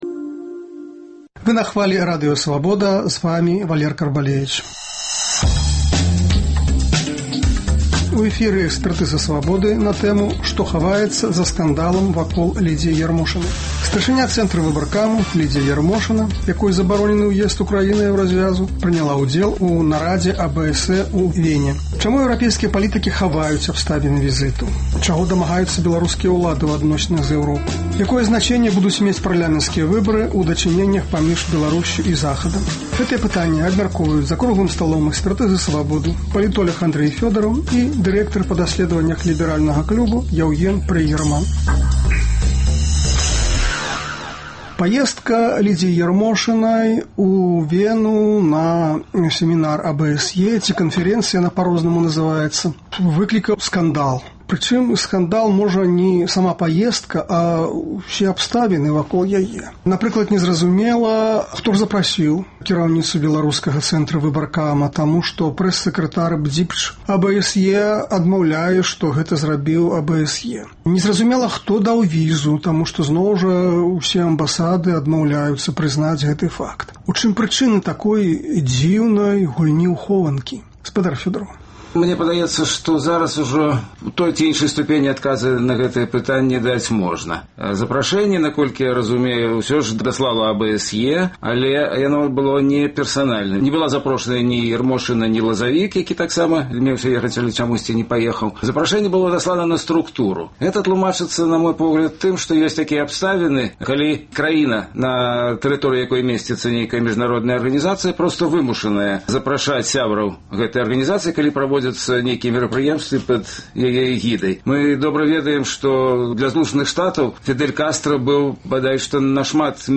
Чаго дамагаюцца беларускія ўлады ў адносінах з Эўропай? Якое значэньне будуць мець парлямэнцкія выбары ў дачыненьнях паміж Беларусьсю і Захадам? Гэтыя пытаньні абмяркоўваюць за круглым сталом